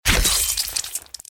bt_slash0.mp3